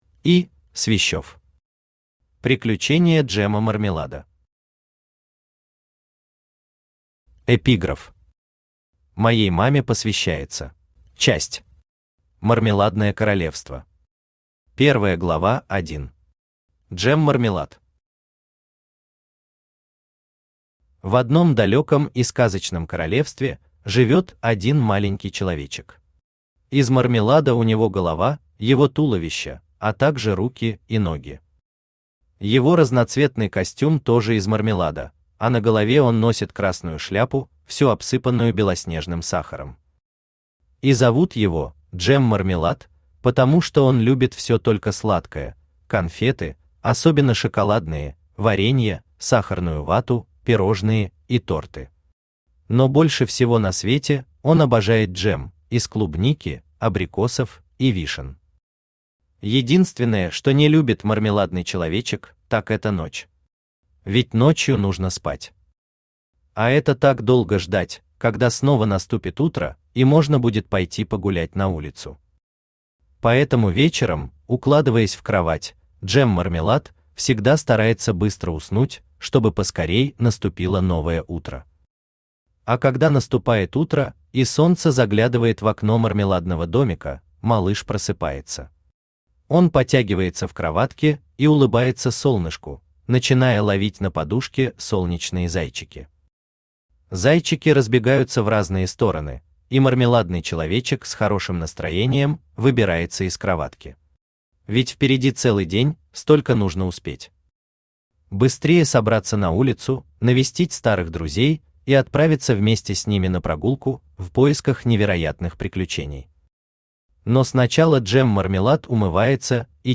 Аудиокнига Приключения Джема Мармелада | Библиотека аудиокниг
Aудиокнига Приключения Джема Мармелада Автор И. Свищёв Читает аудиокнигу Авточтец ЛитРес.